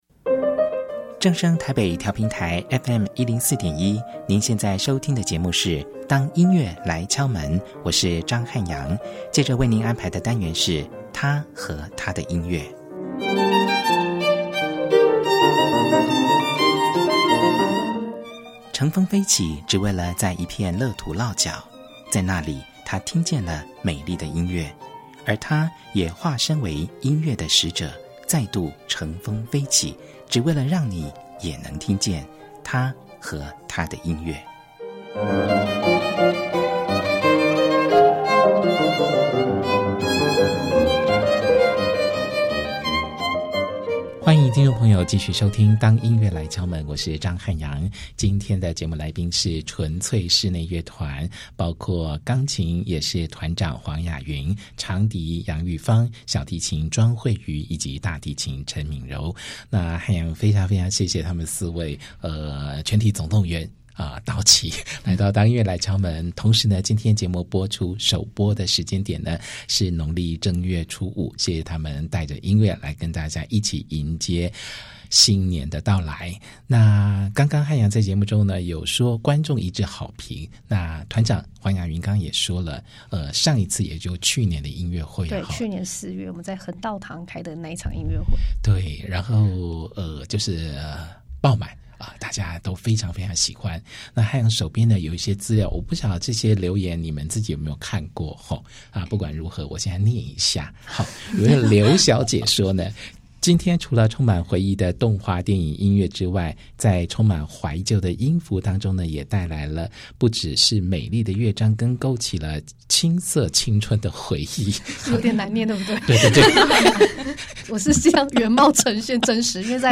現場導聆「動畫音樂之旅」音樂會曲目並即席示範演奏